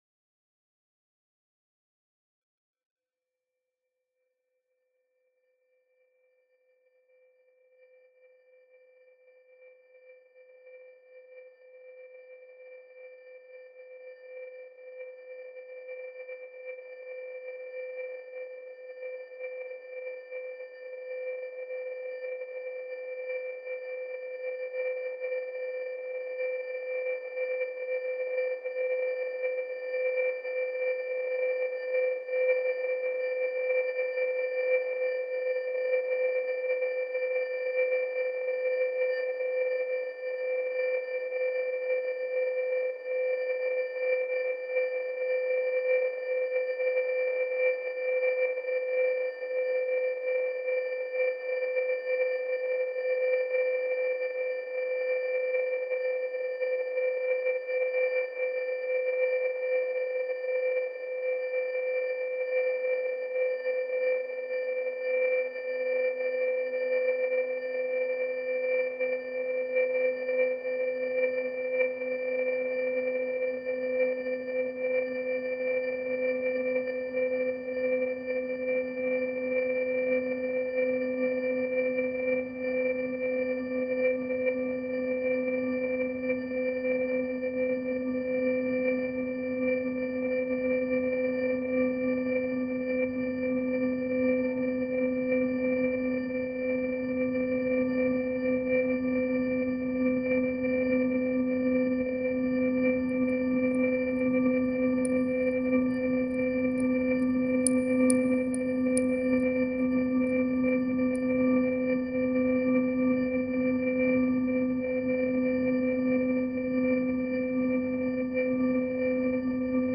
File under: Minimal Ambient / Experimental
each sequence being infused with granular layerings